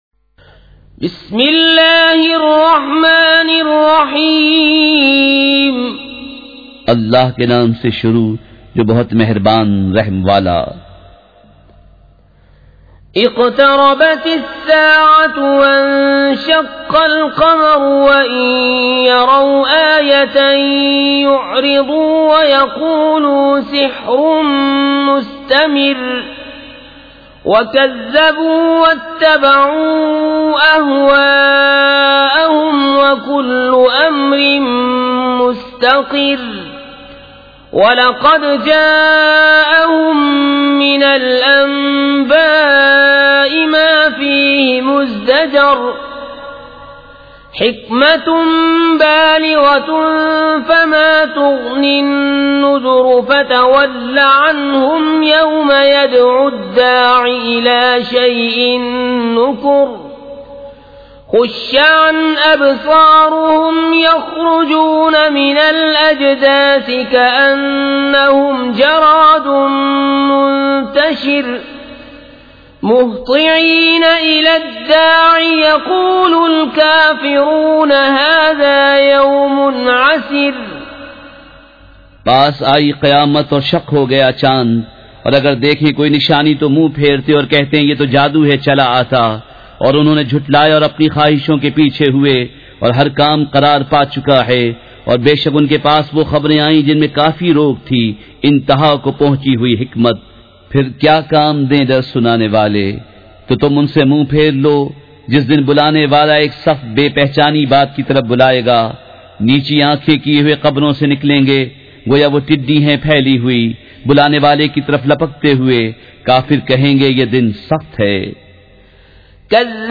سورۃ القمر مع ترجمہ کنزالایمان ZiaeTaiba Audio میڈیا کی معلومات نام سورۃ القمر مع ترجمہ کنزالایمان موضوع تلاوت آواز دیگر زبان عربی کل نتائج 1948 قسم آڈیو ڈاؤن لوڈ MP 3 ڈاؤن لوڈ MP 4 متعلقہ تجویزوآراء